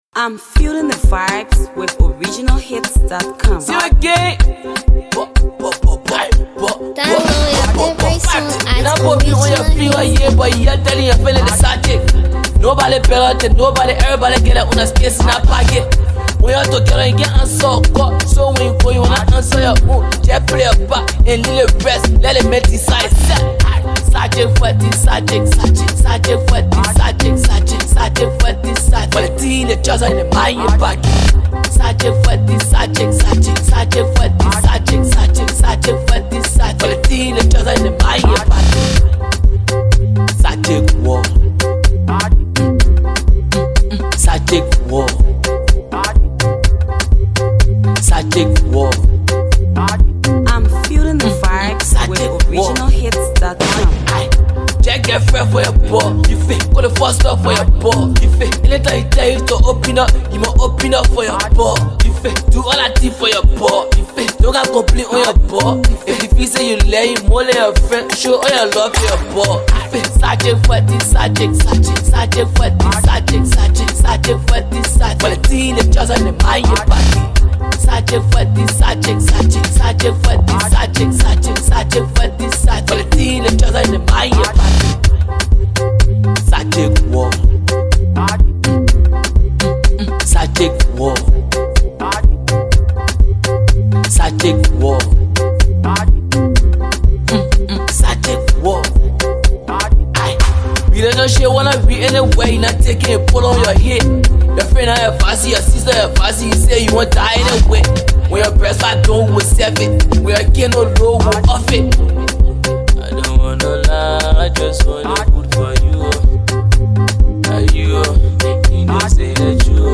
danceable banger